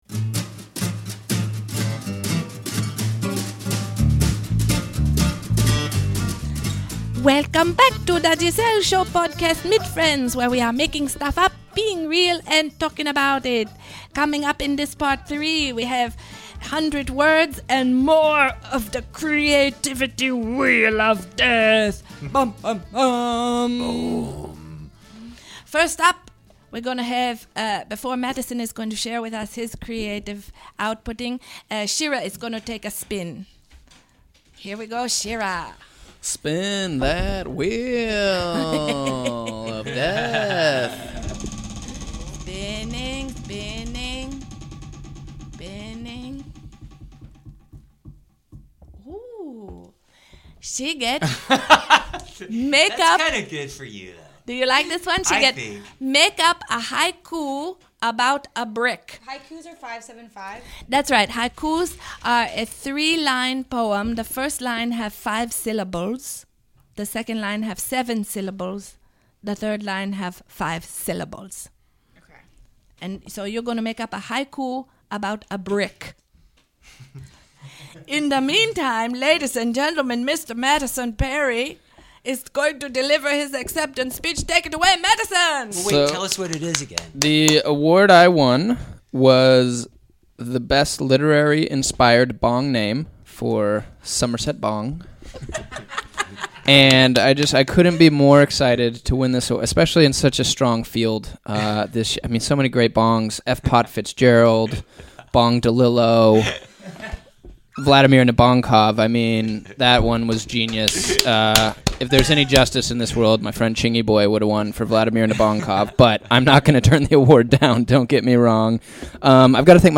Photos from the Live Recording: